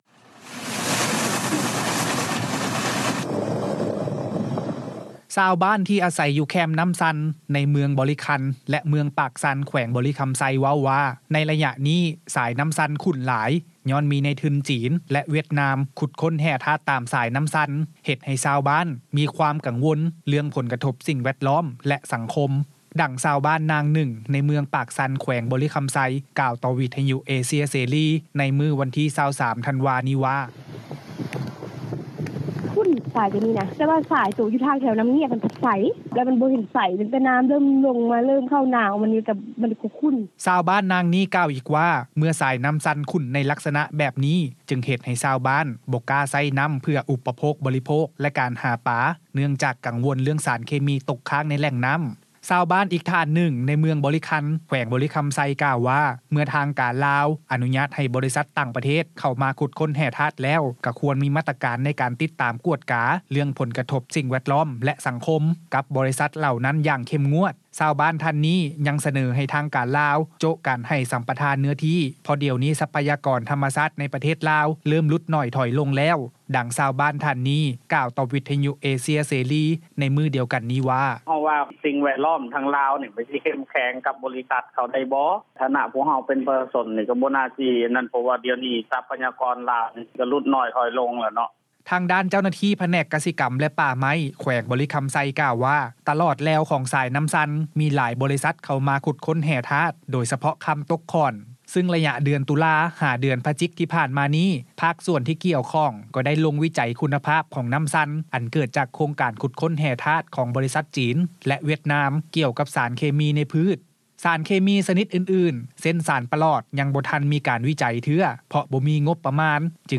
ດັ່ງຊາວບ້ານ ນາງໜຶ່ງ ໃນເມືອງປາກຊັນ ແຂວງບໍລິຄຳໄຊ ກ່າວຕໍ່ວິທຍຸເອເຊັຽເສຣີ ໃນມື້ວັນທີ 23 ທັນວາ ນີ້ວ່າ: